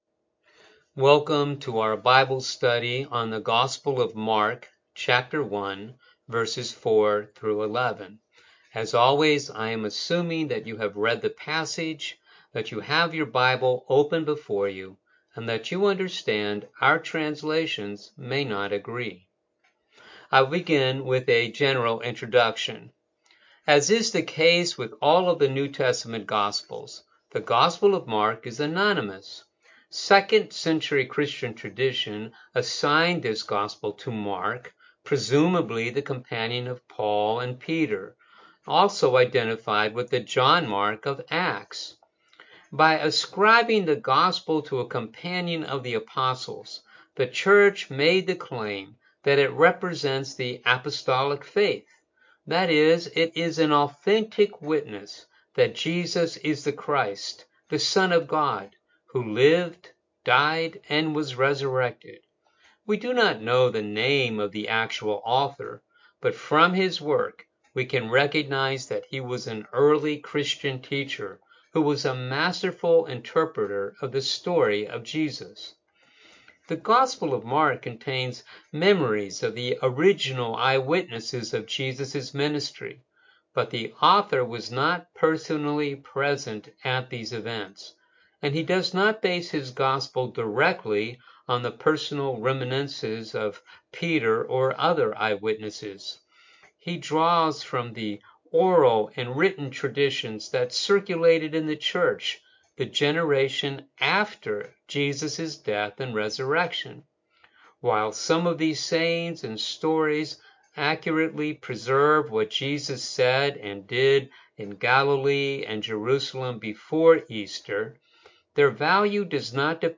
Bible Study Online Click to Hear Sermon